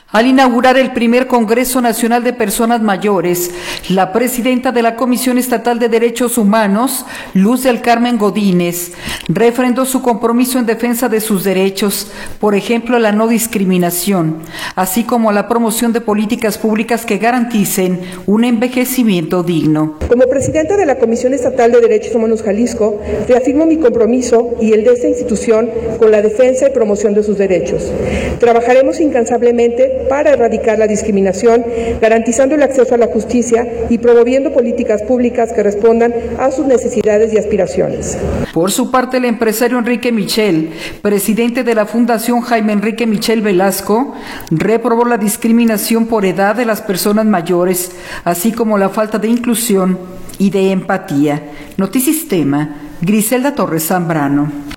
Al inaugurar el Primer Congreso Nacional de Personas Mayores, la presidenta de la Comisión Estatal de Derechos Humanos, Luz del Carmen Godínez, refrendó su compromiso en defensa de sus derechos, por ejemplo a la no discriminación, así como a la promoción de políticas públicas que garanticen un envejecimiento digno.